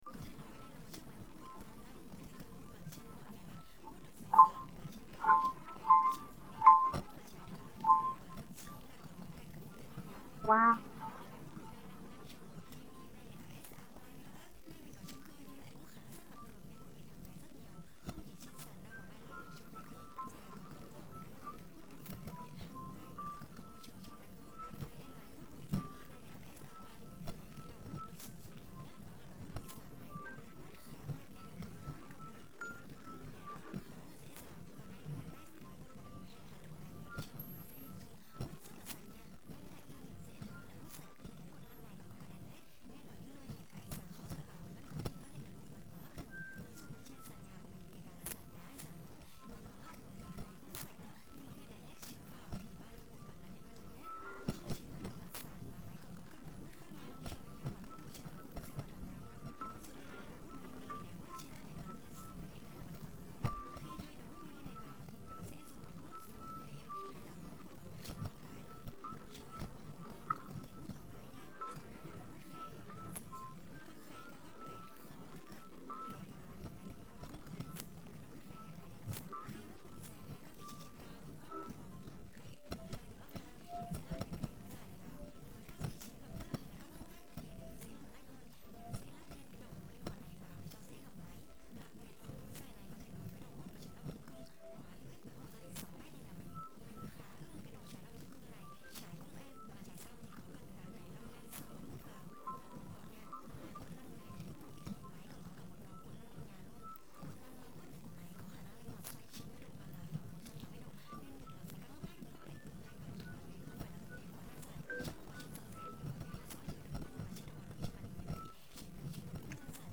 Pozadí hudba